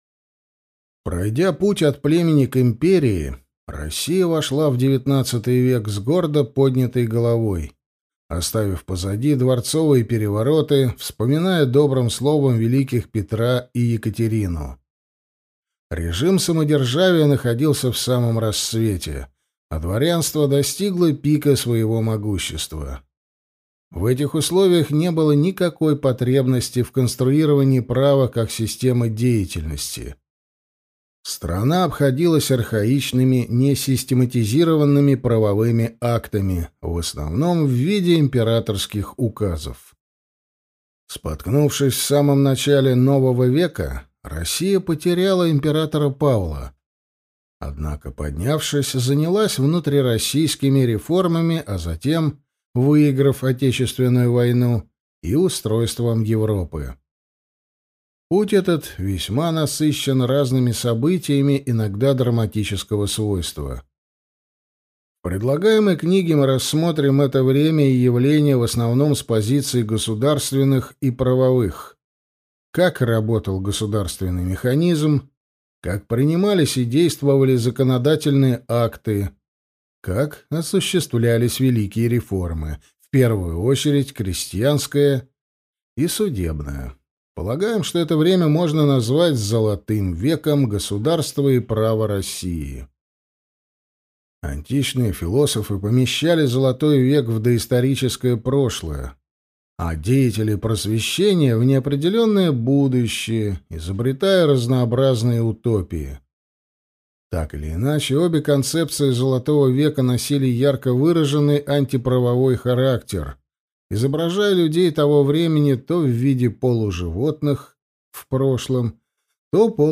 Аудиокнига Время великих реформ. Золотой век российского государства и права | Библиотека аудиокниг